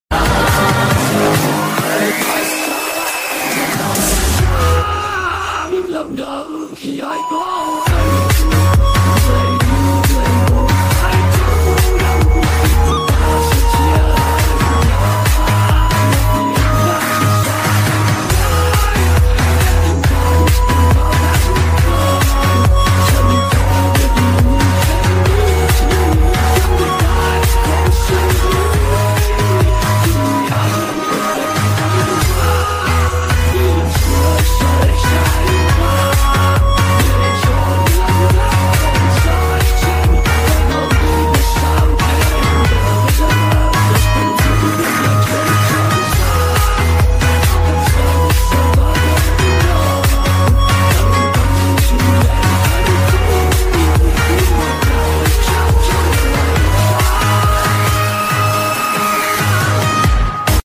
Remix
AI Cover